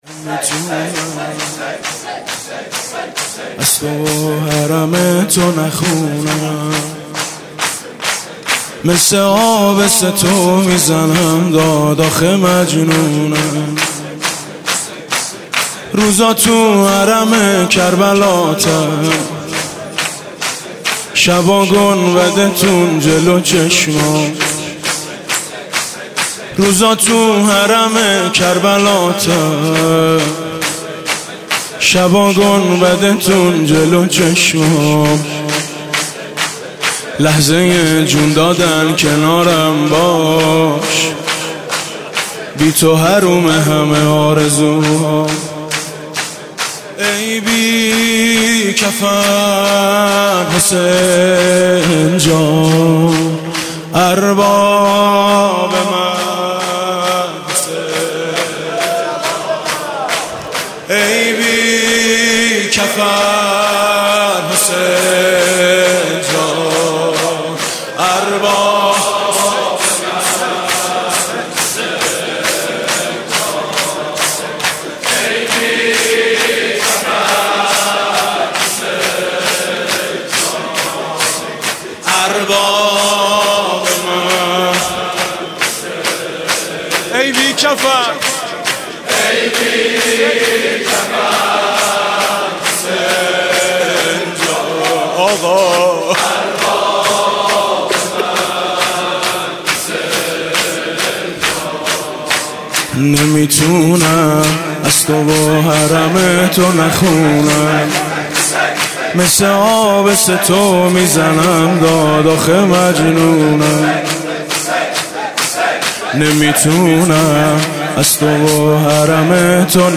شور: ای بی کفن حسین جان